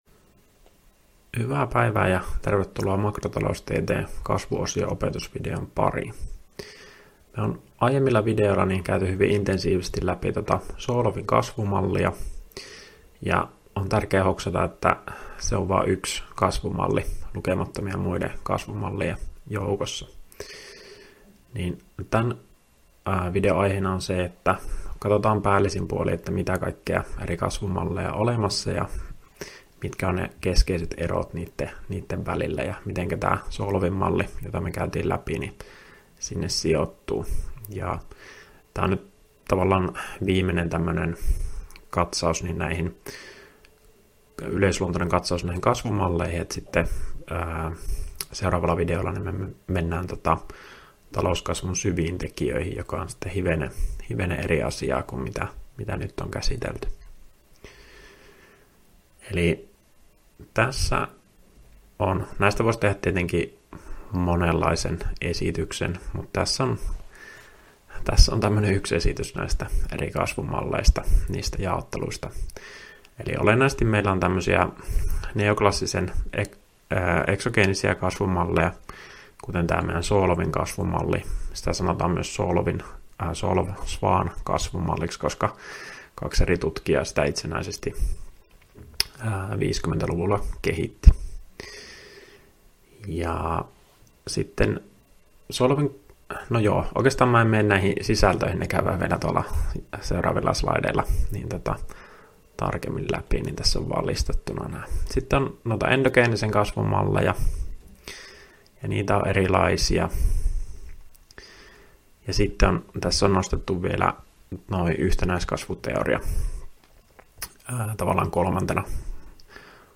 Opintojakson "Makrotaloustiede I" kasvuosion 6. opetusvideo